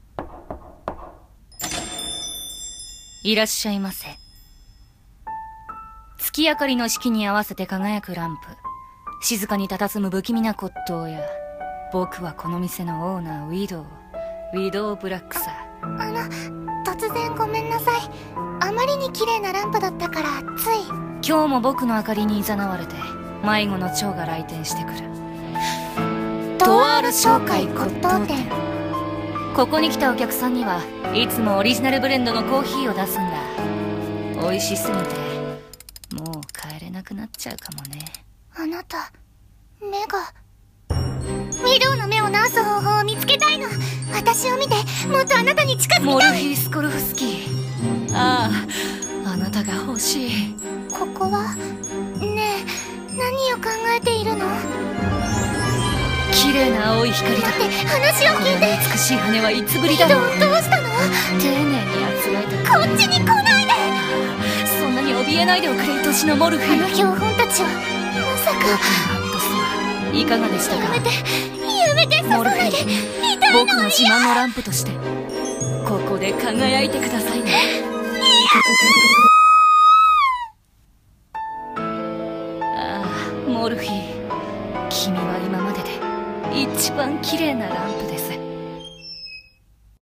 CM風声劇｢トワール商會骨董店｣お手本